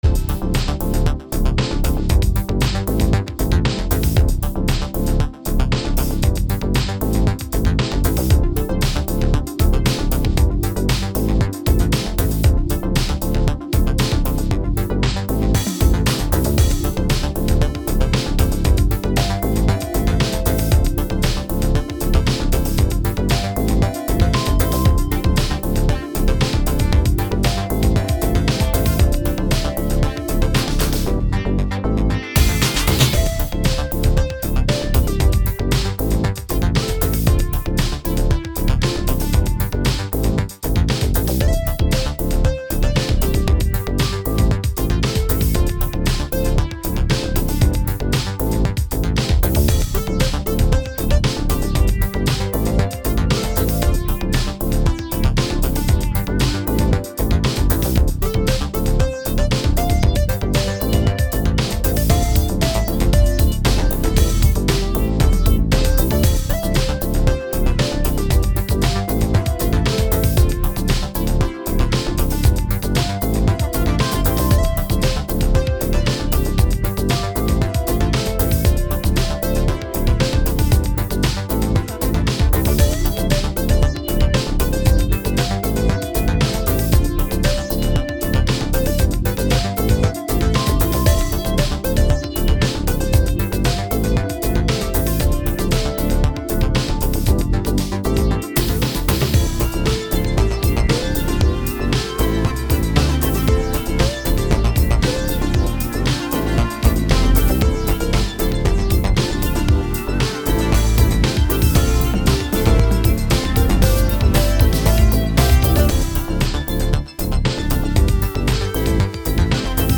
A Funky mix of two tracks